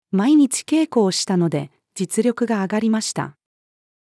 毎日稽古をしたので、実力が上がりました。-female.mp3